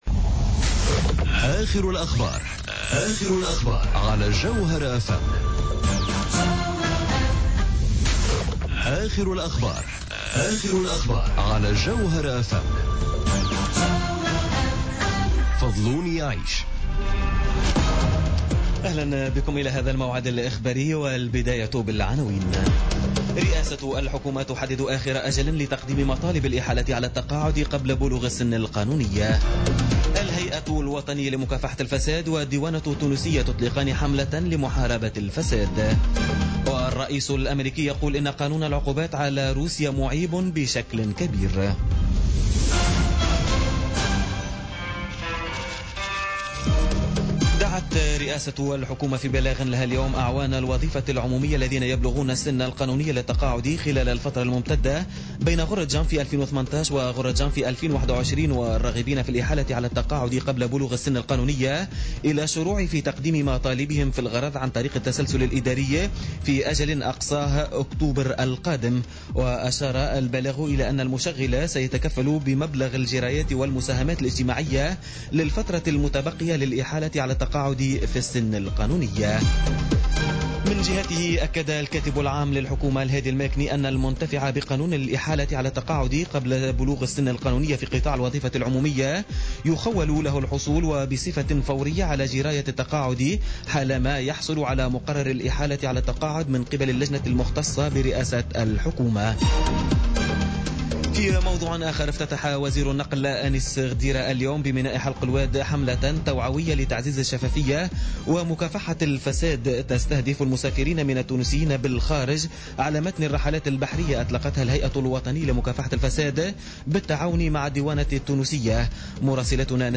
نشرة أخبار السابعة مساء ليوم الأربعاء 02 اوت 2017